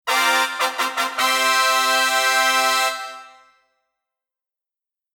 But, to my knowledge, what hasn’t been brought out until today, on this website… (cue trumpets)